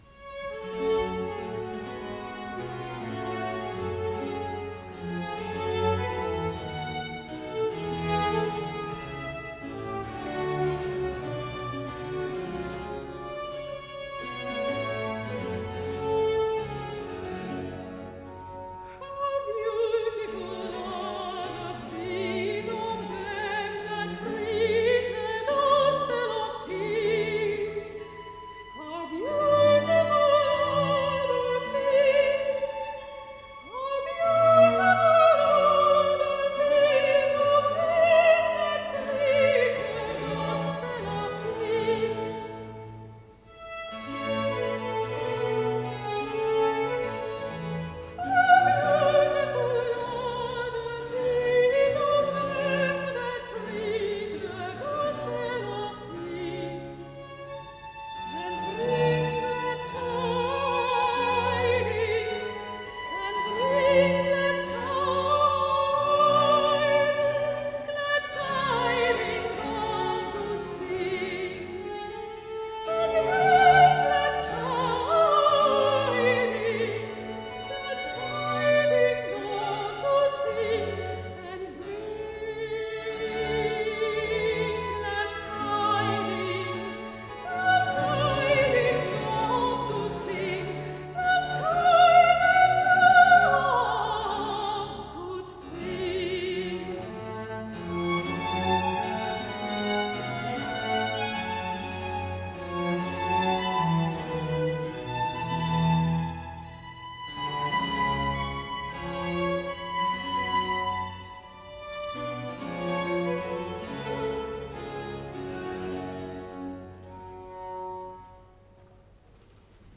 Aria-soprano